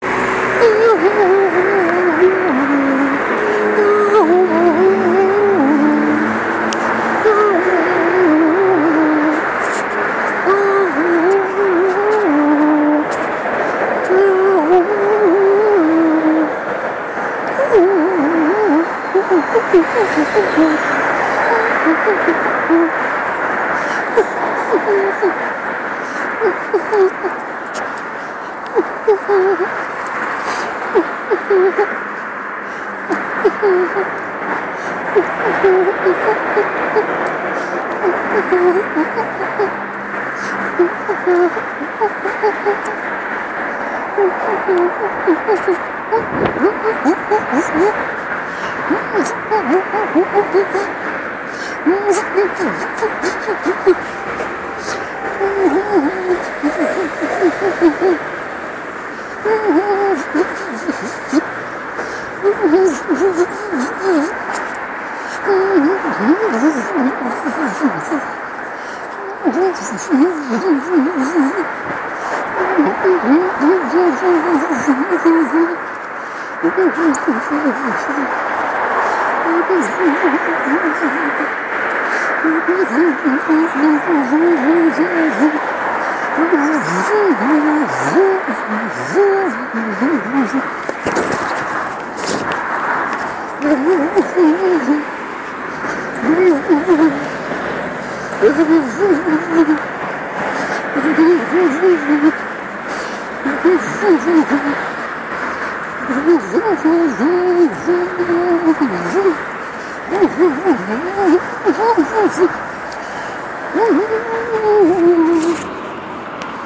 Vocal improv against traffic noise (with accidental trip)